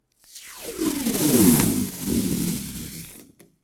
Tirar de una cinta de embalar
Sonidos: Oficina